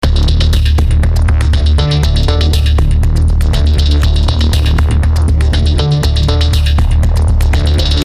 B25发动机启动
描述：B25轰炸机在航展上录制。发动机旋转咳嗽，乘出租车。 Rode NTG1,24 bit 48KHz
标签： 航空 飞机 轰炸机 飞机 军用
声道立体声